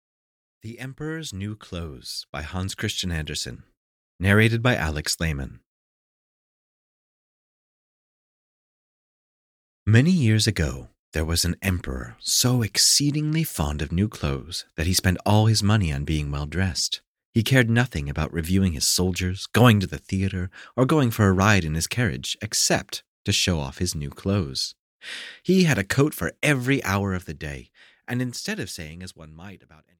The Emperor's New Clothes (EN) audiokniha
Ukázka z knihy